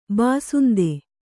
♪ bāsunde